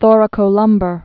(thôrə-kō-lŭmbər, -bär)